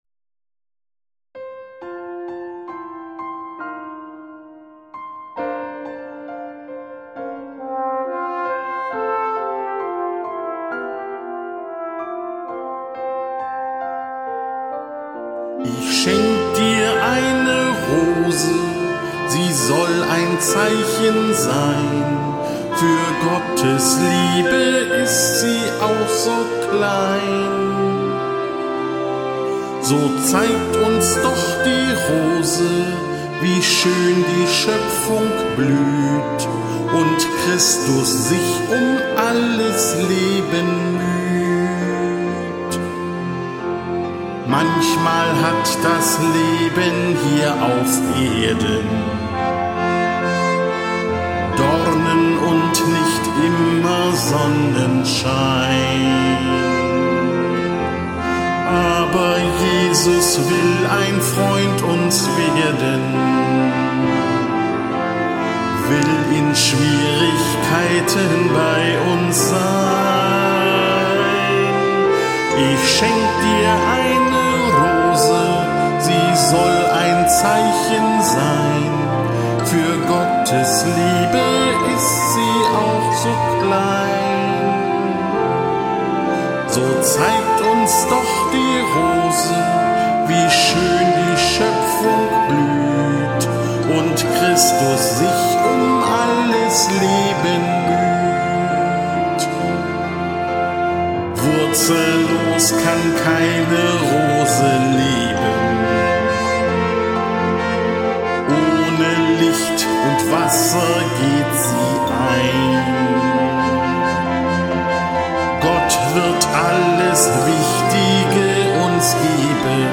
Ich-schenk-dir-eine-Rose-Gesang.mp3